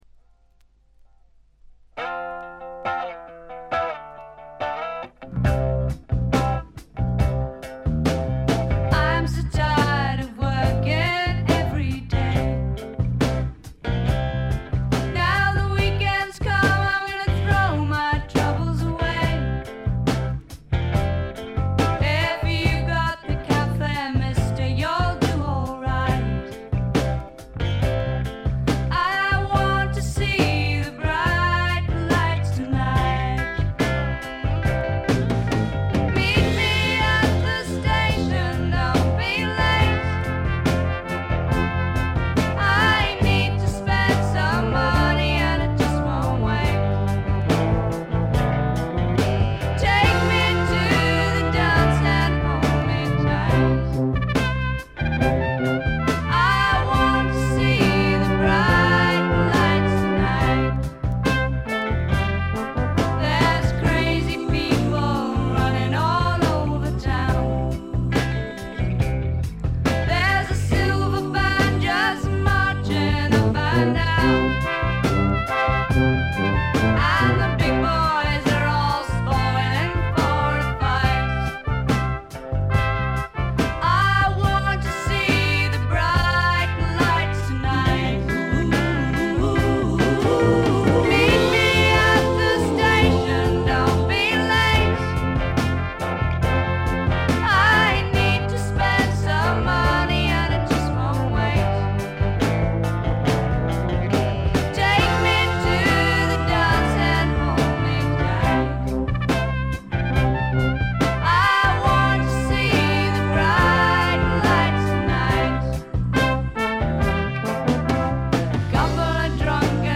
部分試聴ですが、ほとんどノイズ感無し。
英国のフォークロックはこう来なくっちゃというお手本のようなもの。
試聴曲は現品からの取り込み音源です。